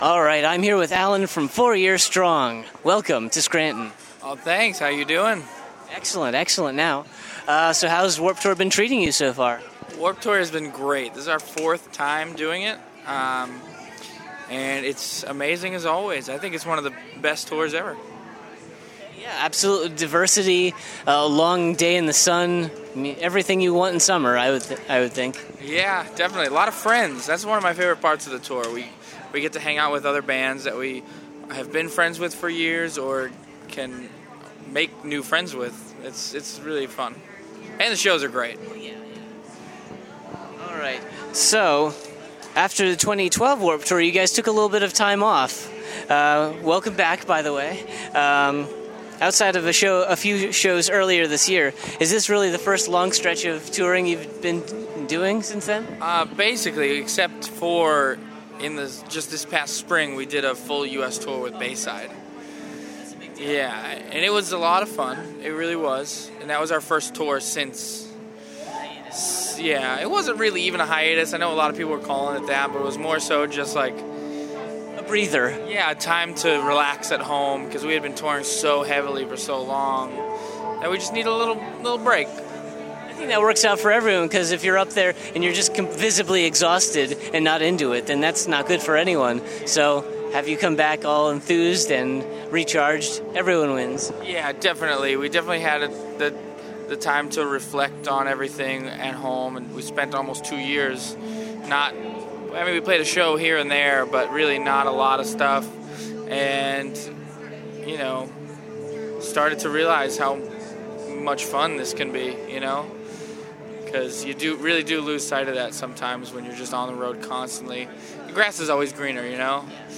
Exclusive: Four Year Strong Interview
52-interview-four-year-strong.mp3